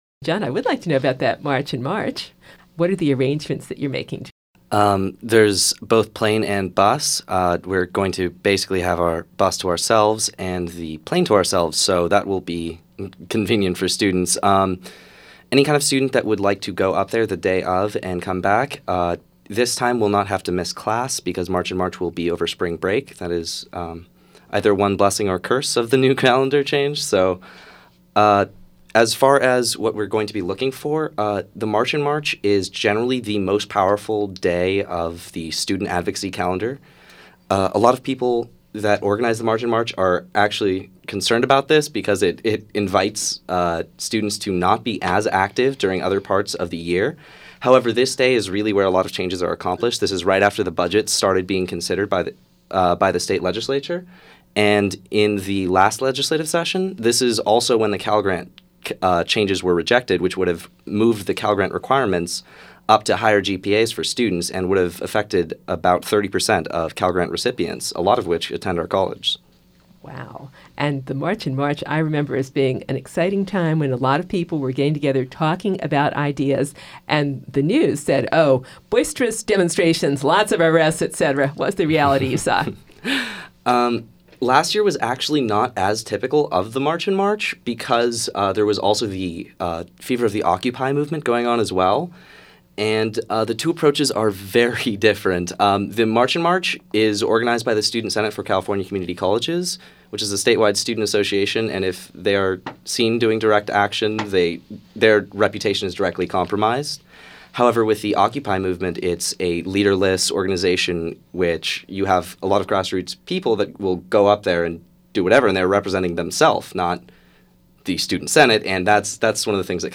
Associated Students Interview, Part Three